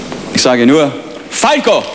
I did this connecting my VCR headphone socket to the computer's "line in" plug, okay? Not always terribly good quality...
Peter Alexander announcing Falco in his TV show.